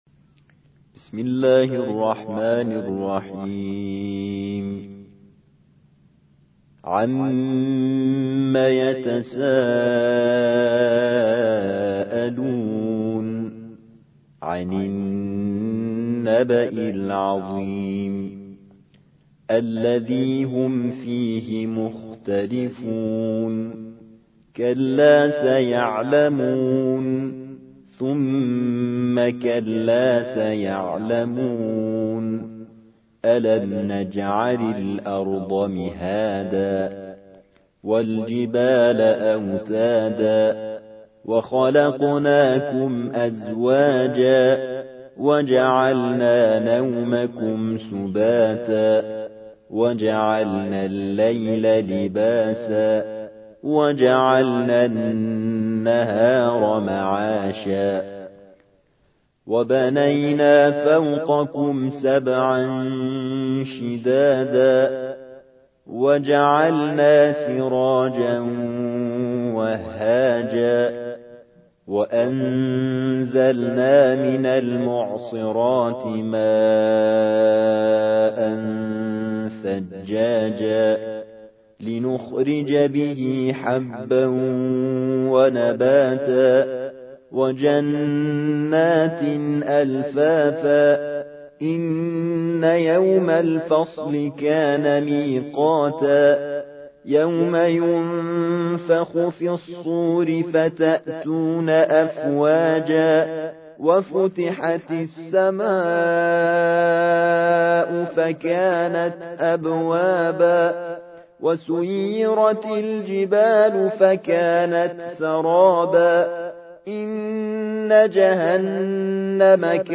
78. سورة النبأ / القارئ